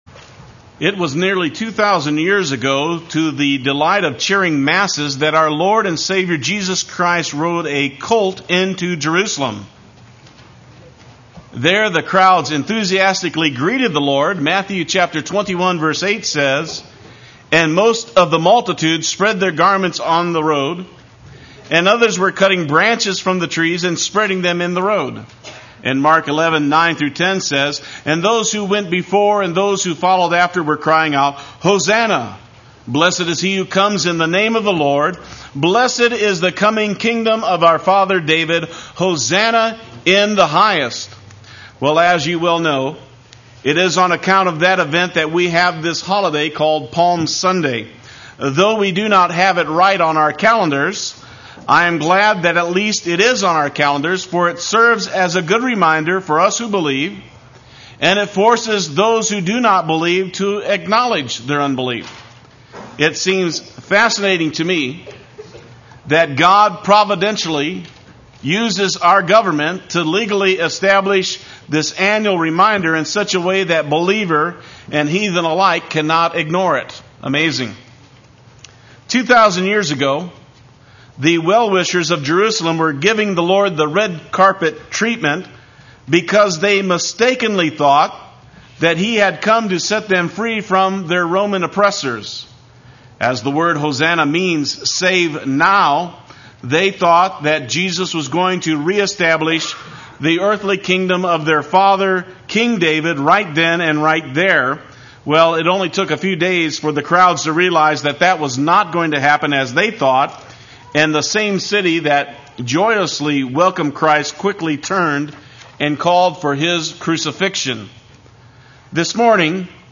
Play Sermon Get HCF Teaching Automatically.
The Glory of God in the Scandal of the Cross Sunday Worship